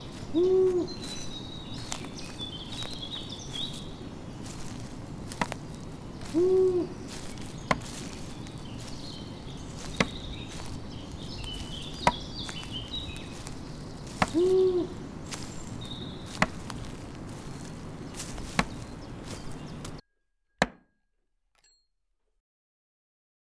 Sons-forêt-hâche.mp3